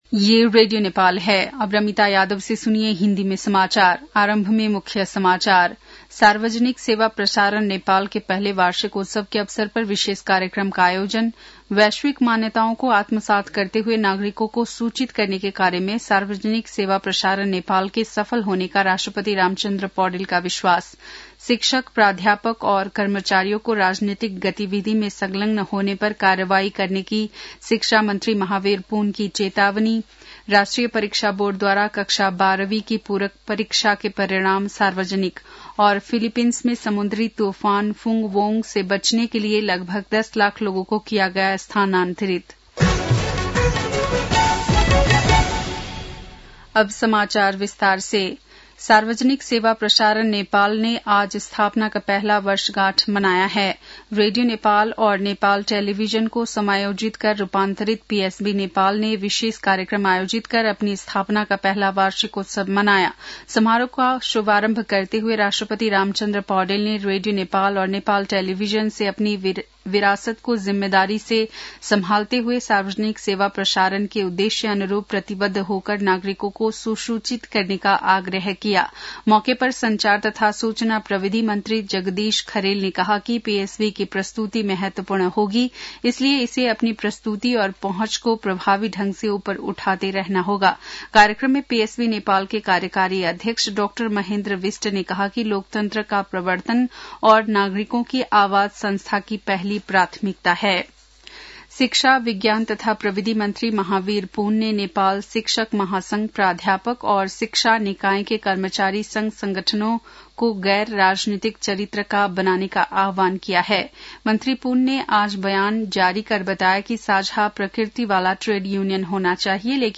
बेलुकी १० बजेको हिन्दी समाचार : २३ कार्तिक , २०८२
10-pm-hindi-news-7-23.mp3